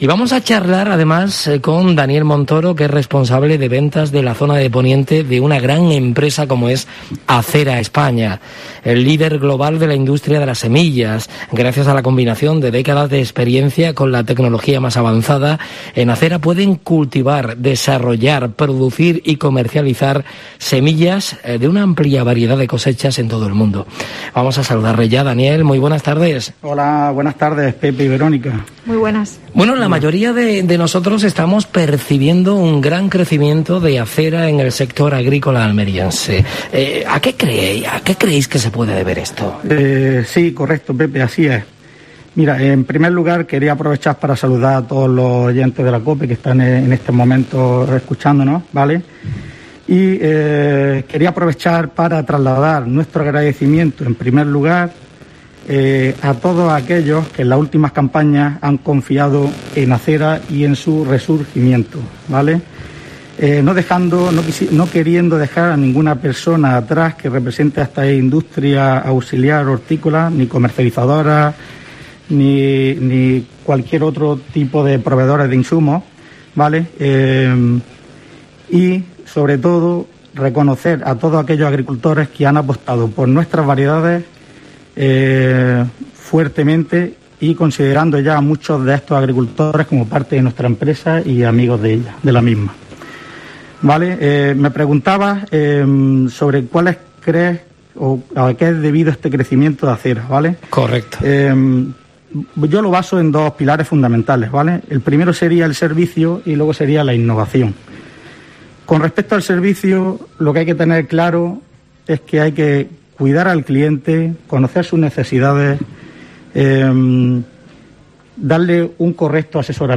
La empresa líder global en la industria de semillas, protagonista en los programas especiales de COPE Almería desde Viagro.